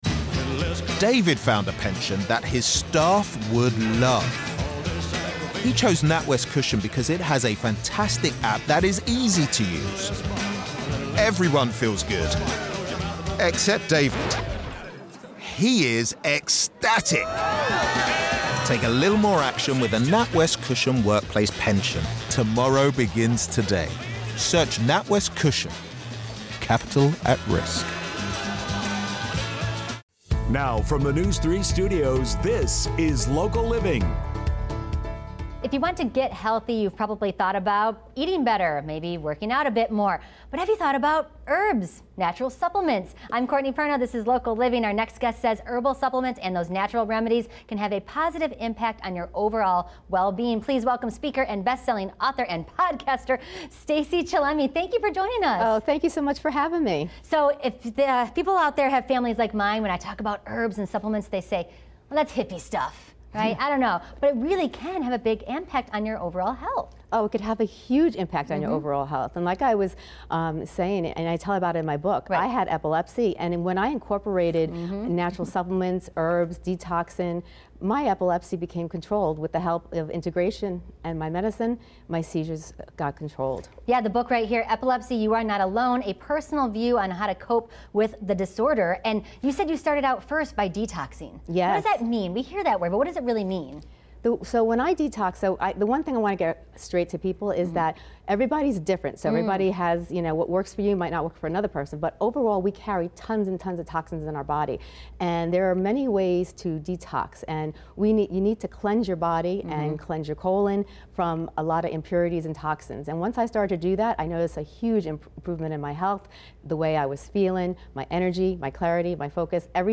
Interviewed by NBC